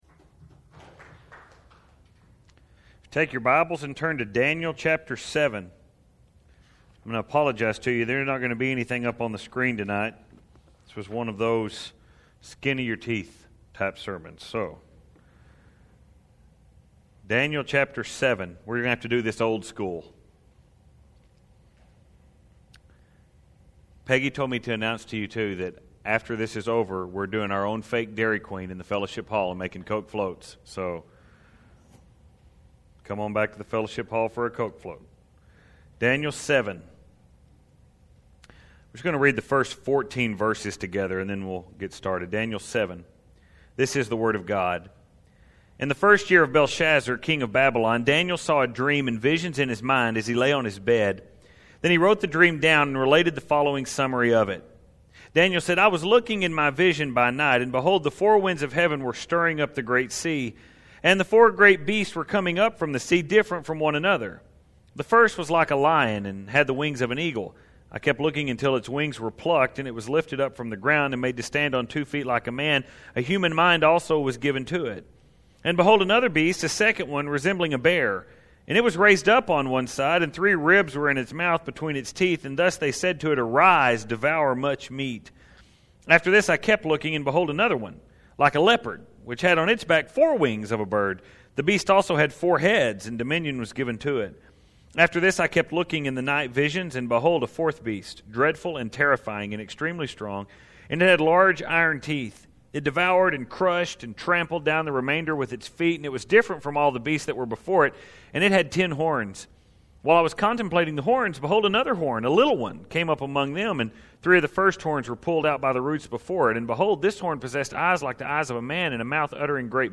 Choosing Your Kingdom Daniel 7:1-28 June 19, 2016 (No notes available for this sermon)